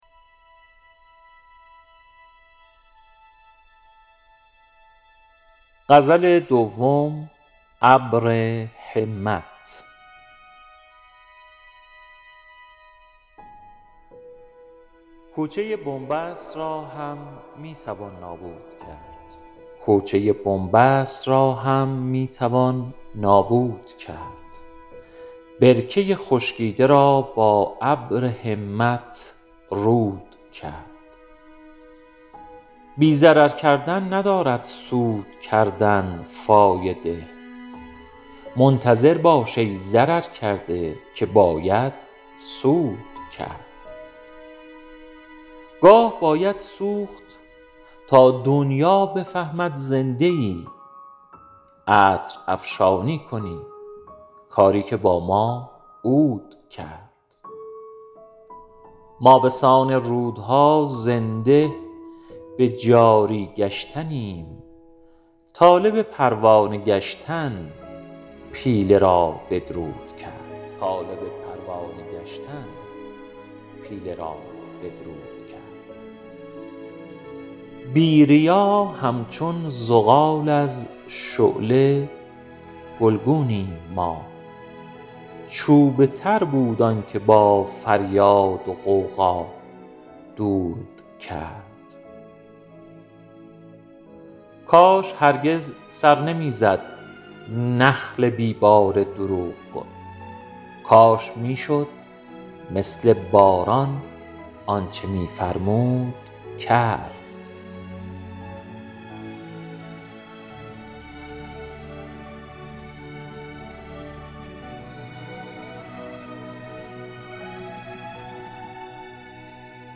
کتاب‌های صوتی ثبت دیدگاه 83 بازدید